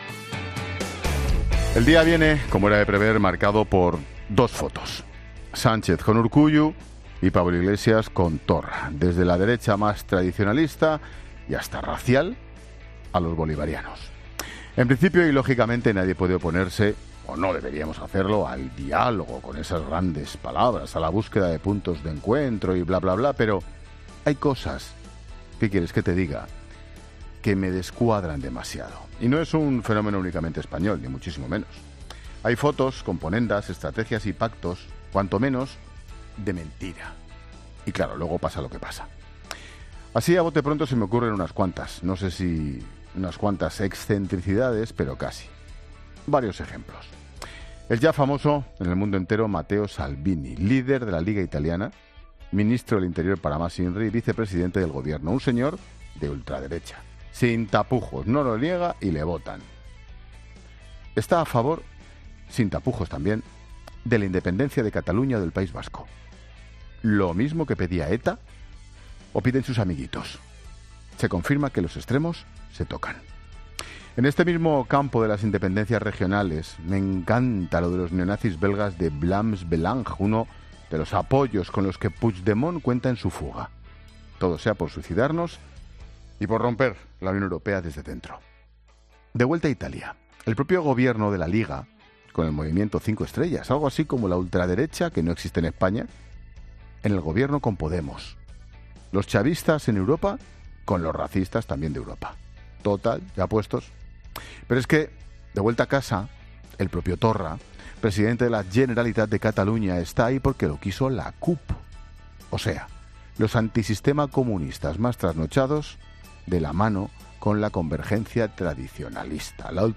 Monólogo de Expósito
El comentario de Ángel Expósito.